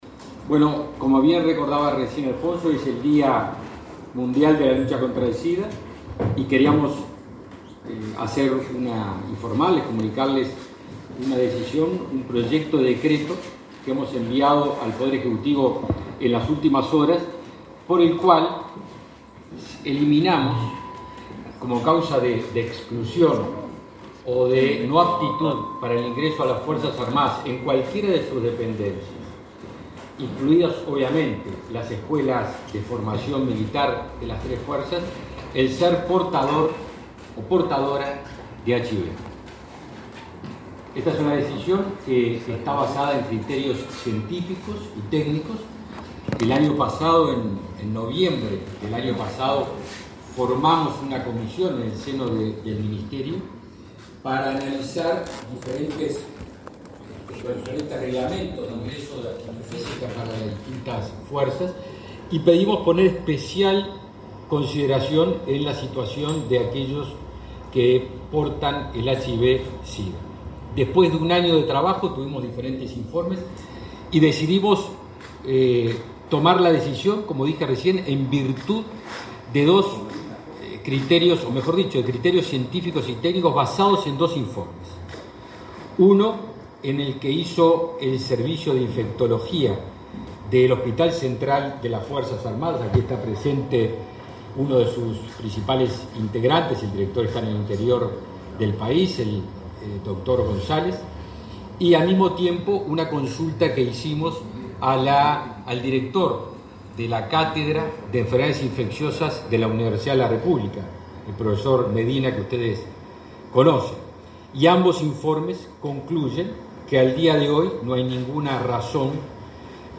Palabras del ministro de Defensa, Javier García
El ministro de Defensa, Javier García, informó este miércoles 1.° a la prensa sobre los cambios en los criterios de ingresos a las Fuerzas Armadas